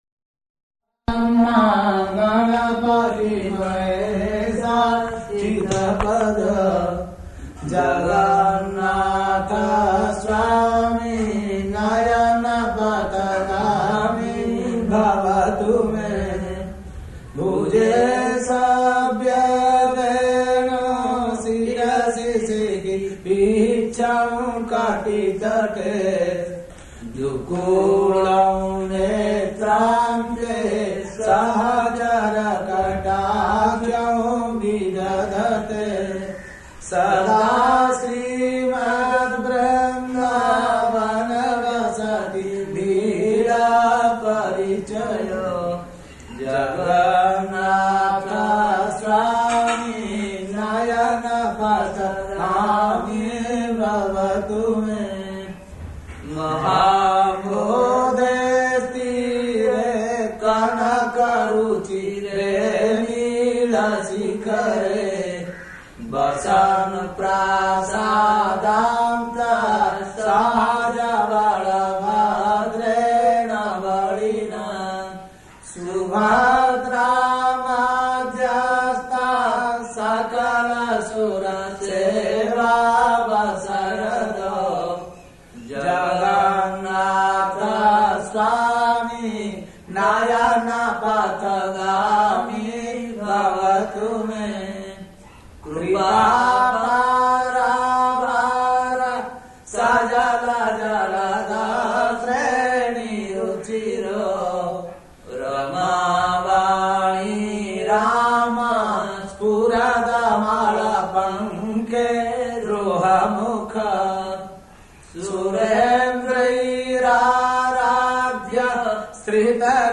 [Purī temple brāhmaṇas chant prayers to Lord Jagannātha, loud pūjā going on in background throughout]